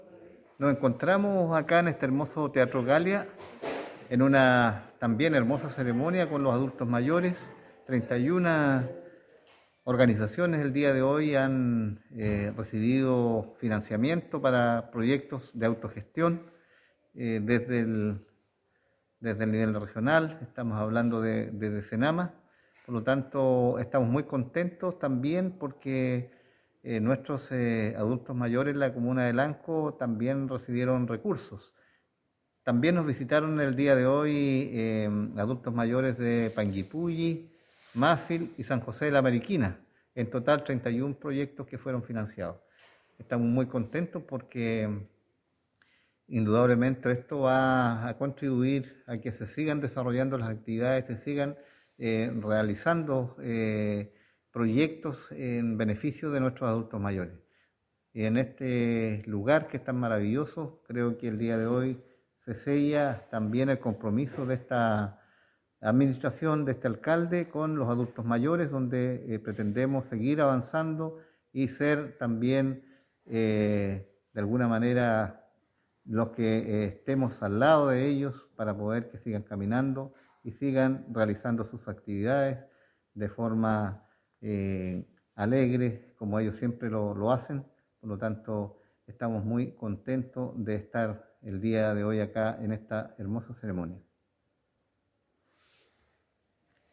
A la ceremonia realizada en el Teatro Galia de la comuna de Lanco, asistieron el alcalde de la comuna de Lanco, Juan Rocha Aguilera, la Seremi de Desarrollo Social y Familia Srta. Ann Hunter Gutiérrez, Patricio Ordóñez, Coordinador (S) de Servicio Nacional del Adulto Mayor, (SENAMA), el Senador Alfonso de Urresti, los diputados Marcos Ilabaca e Iván Flores, junto al Core, Ítalo Martínez y la concejala, Pamela Ramírez.
Cuna-Alcalde-Juan-Rocha-entrega-certificaciones-fondo-SENAMA.mp3